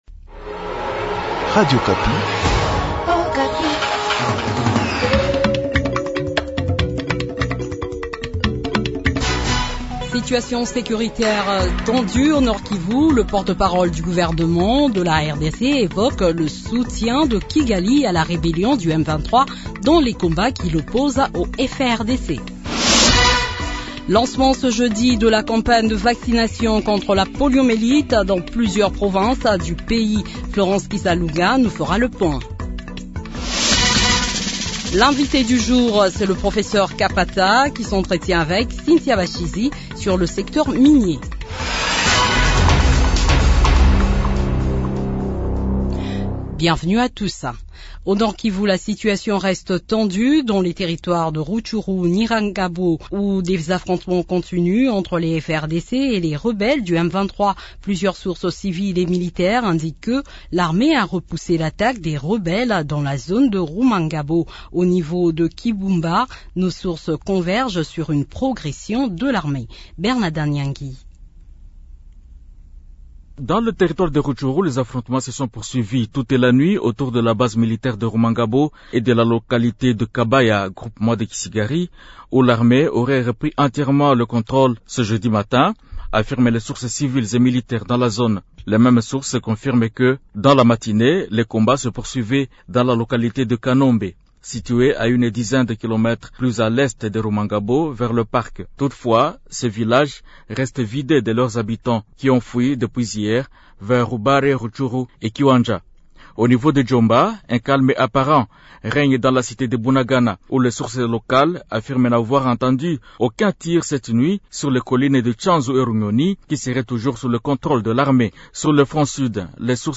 Le journal de 12 heures du 26 mai 2022